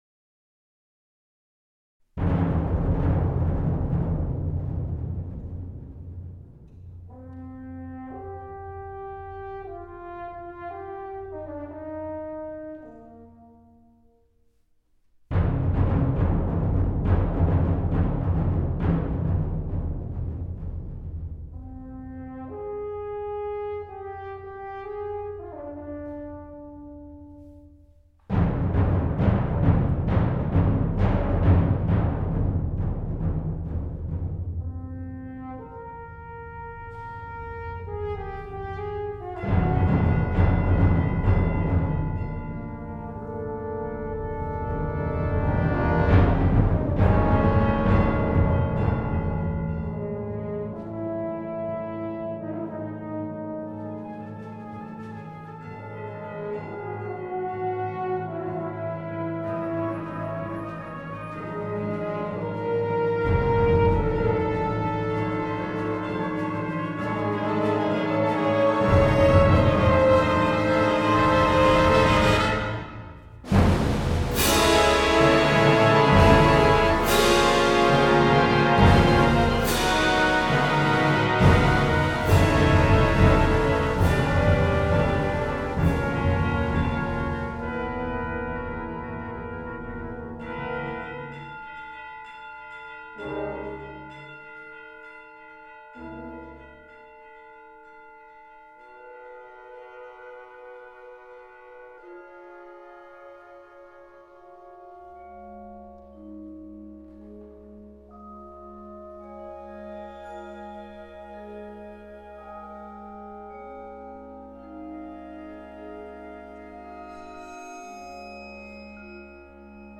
Voicing: Concert Band Level